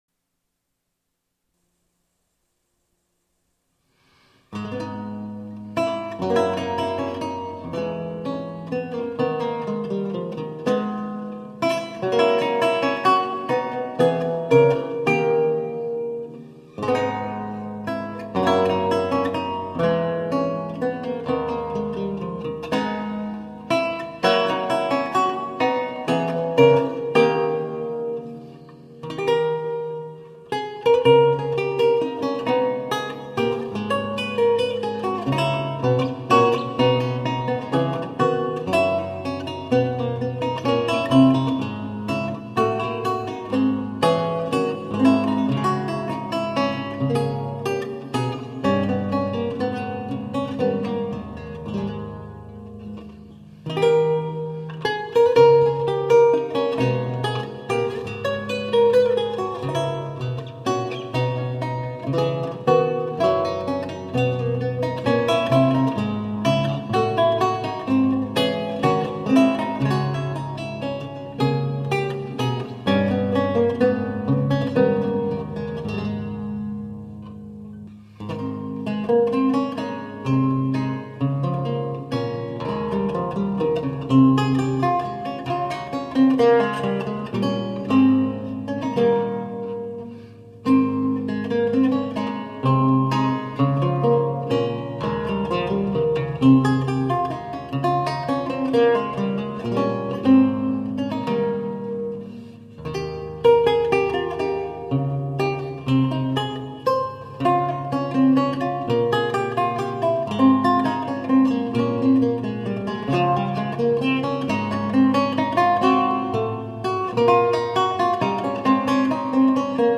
Classical, Baroque, Instrumental